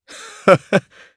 Chase-Vox-Laugh_jp.wav